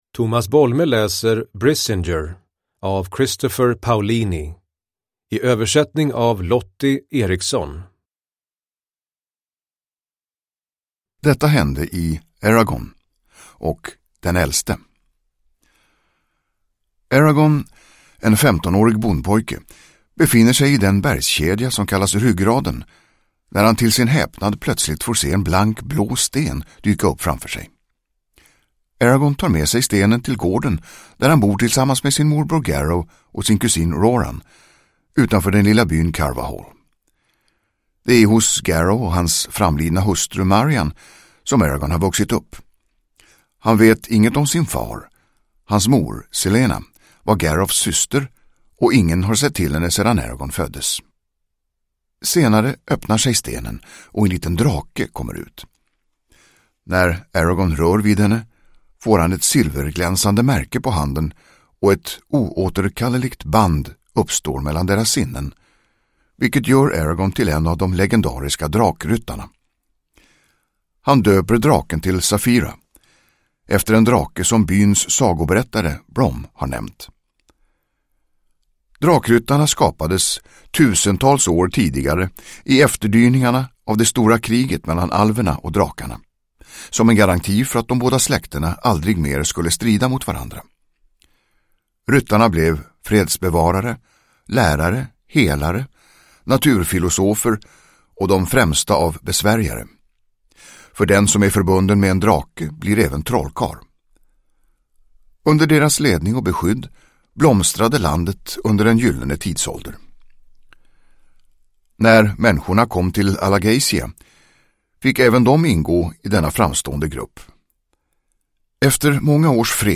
Produkttyp: Digitala böcker
Uppläsare: Tomas Bolme